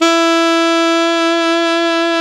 SAX ALTOMP09.wav